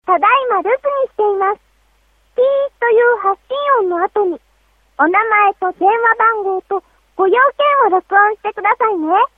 キャリア別通話音質
実際の通話を録音しました。
RealPlayer   声のみ抽出自然な音質。
音楽は無理っぽい。
J-T04_voice.mp3